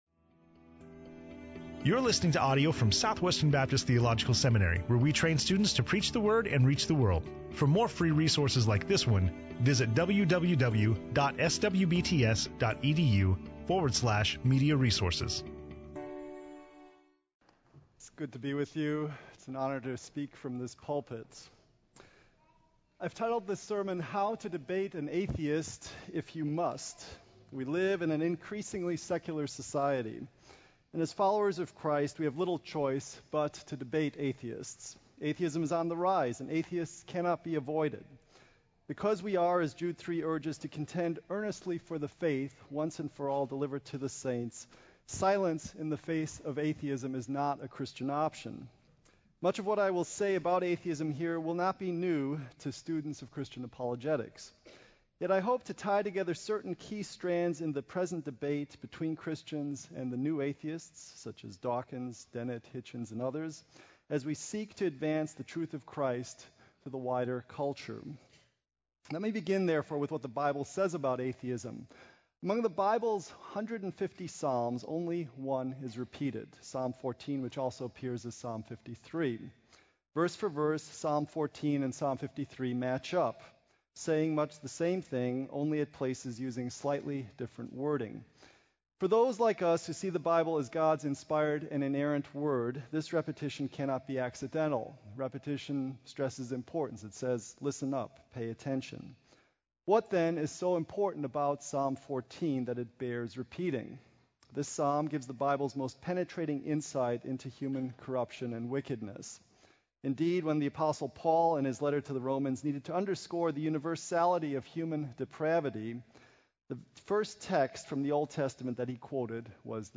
Dr. William Dembski speaking on Romans 3:10-12; Psalm 14:1-3 in SWBTS Chapel on Wednesday September 7, 2011
SWBTS Chapel Sermons William Dembski - SWBTS Chapel - September 7, 2011 Play Episode Pause Episode Mute/Unmute Episode Rewind 10 Seconds 1x Fast Forward 30 seconds 00:00 / Subscribe Share RSS Feed Share Link Embed